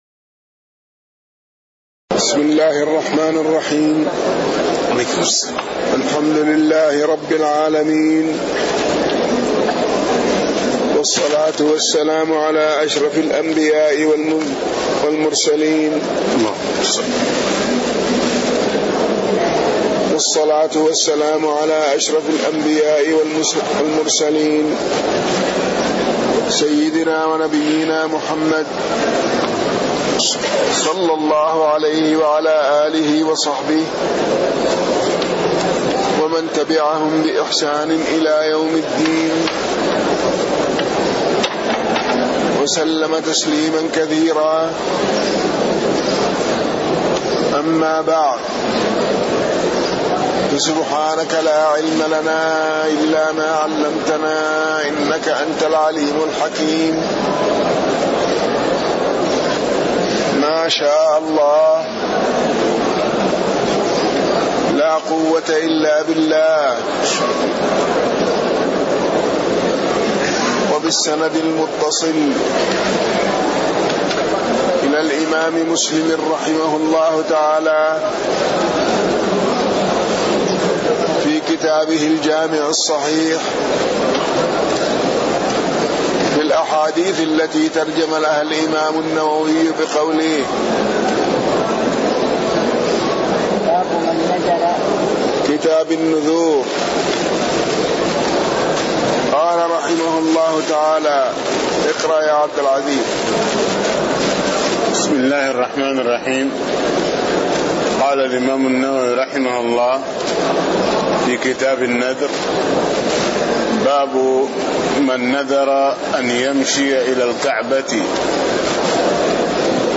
تاريخ النشر ٣ جمادى الأولى ١٤٣٥ هـ المكان: المسجد النبوي الشيخ